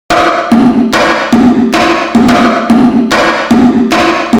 Percussion, even fast percussion like in the following sample, can benefit from a little reverb.
Even if that reverb patch sounds like a jumbled mess right now, that’s just because the volume is way too loud for that sort of instrument.
bongobefore.mp3